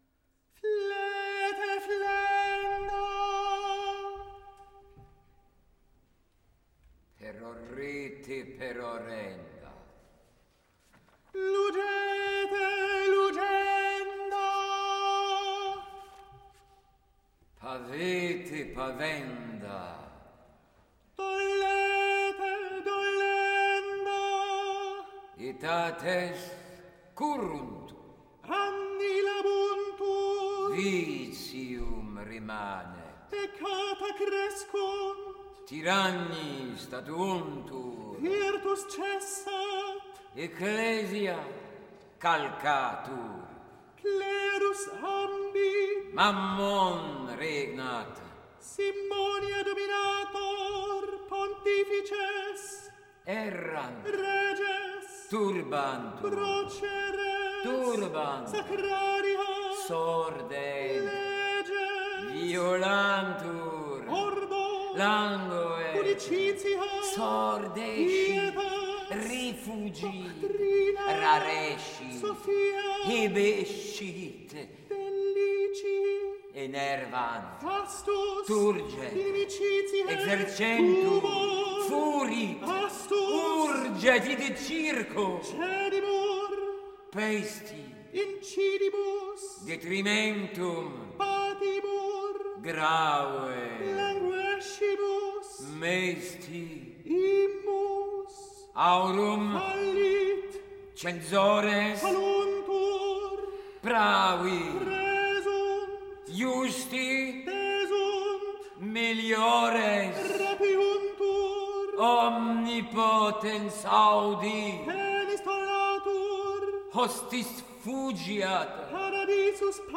* Carmina Burana , 13th century original version, one of our most popular and successful programmes - 6 musicians.